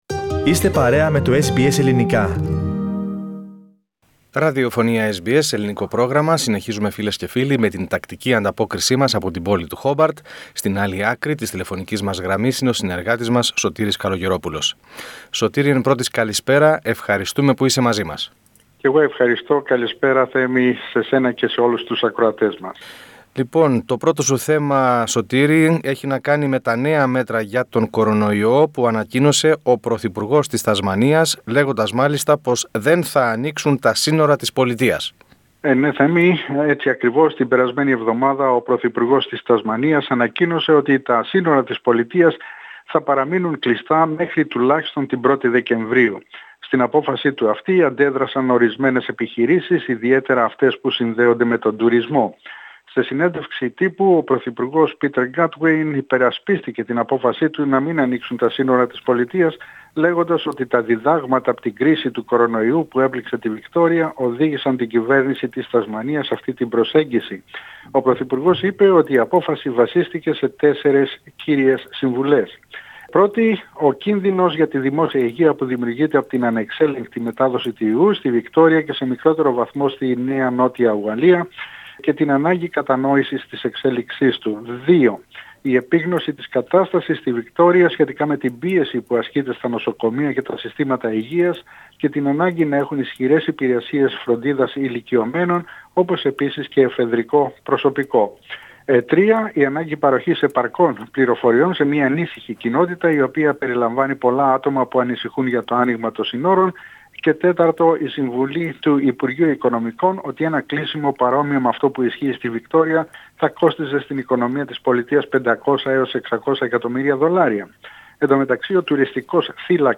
Περισσότερα στην ανταπόκριση